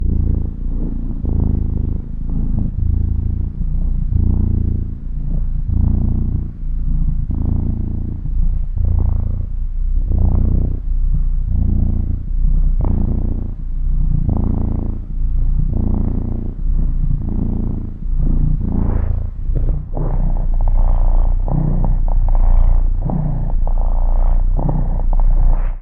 Звуки мурчания и урчания кошек
Любимое, блаженное и расслабляющее мурлыканье (моего) кота
lyubimoe_blazhennoe_i_rasslablyayushee_murlikane_moego_kota_i4y.mp3